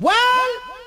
TM88 WhaVox.wav